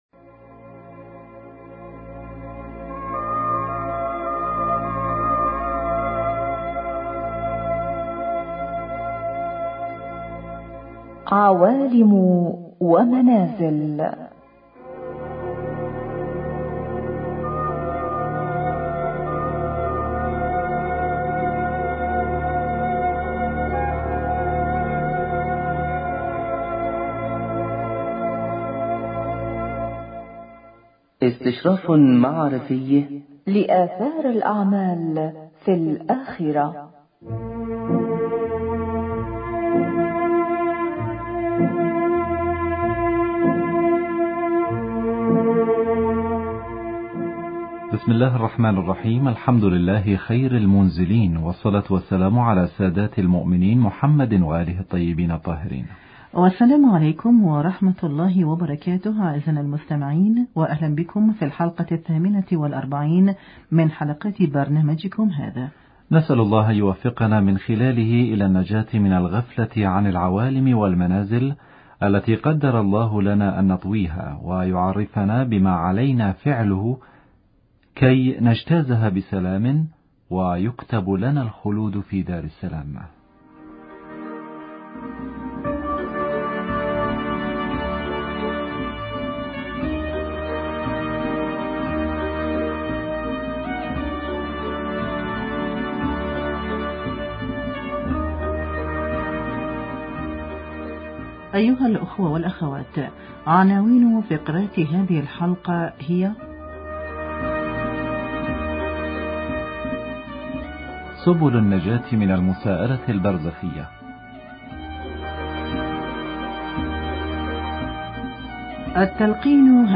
في خلق الجنة والنار التلقين واثره في اعانة المتوفى على مساءلة منكر ونكير فقرة ادبية عنوانها الفطنة في اعمار الوطن